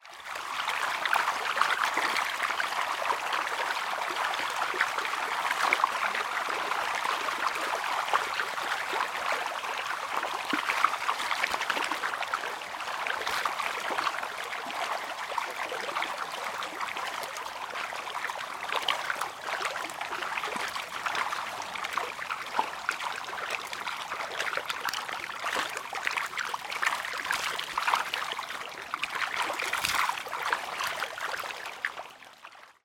Звуки пираний
Звук плеска пираний во время кормления рыб